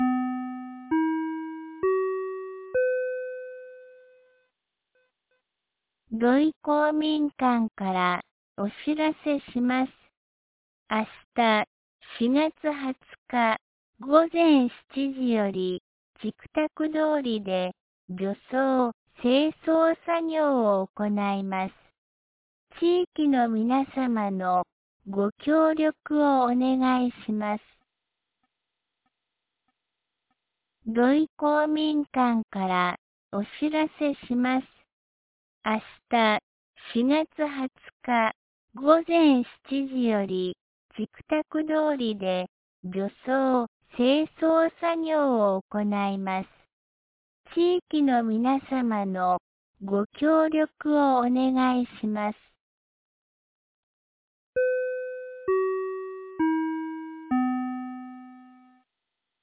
2025年04月19日 17時11分に、安芸市より土居、僧津へ放送がありました。